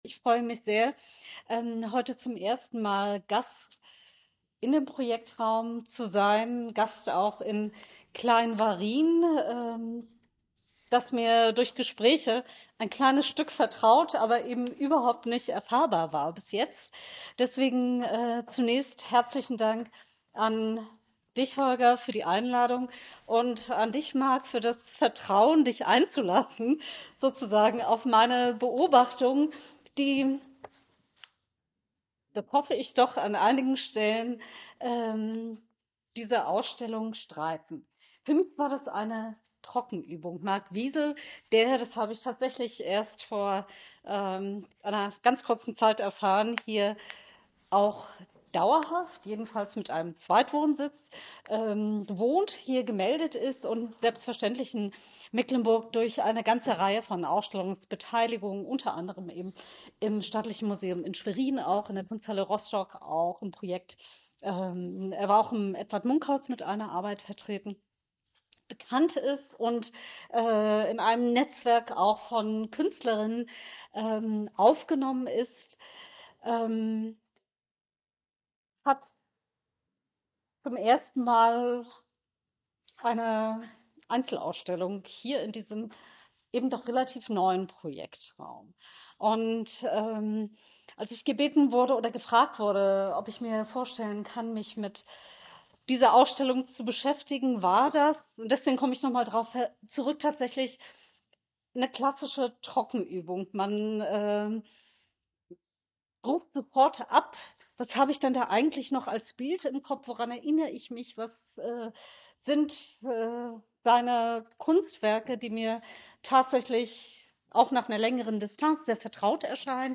Eröffnungsrede